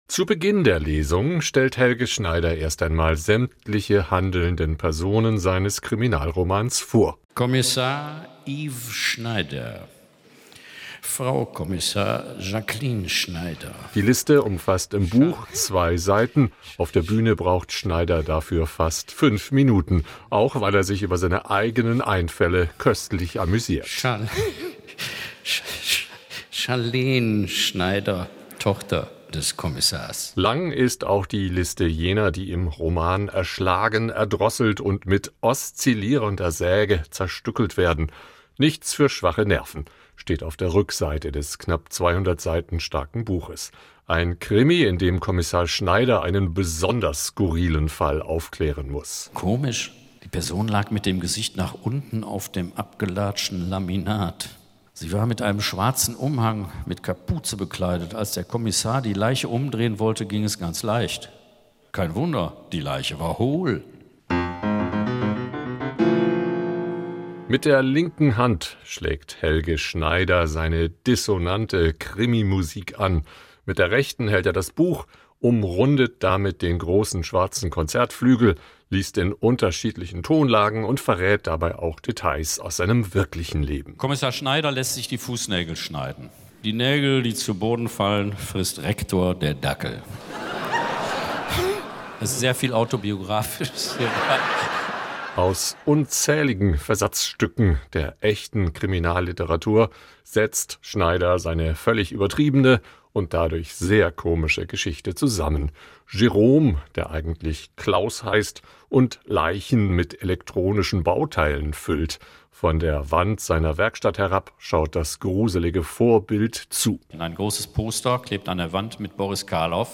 "Stepptanz": Helge Schneider liest aus seinem neuen Roman
Helge Schneider ist kauziger Kabarettist, Filmschauspieler, Jazz-Musiker und Roman-Autor: Am Wochenende hat er im Haus des Rundfunks im Rahmen der „Schönen Lesung“ von radioeins sein mittlerweile zehntes Buch „Stepptanz“ vorgestellt – mitsamt gelegentlicher Musik-Ausflüge.